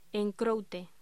Locución: En croute